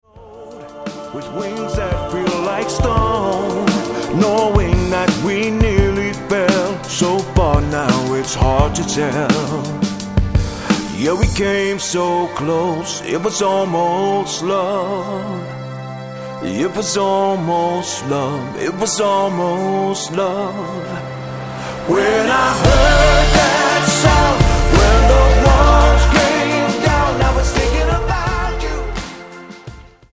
--> MP3 Demo abspielen...
Tonart:Dm Multifile (kein Sofortdownload.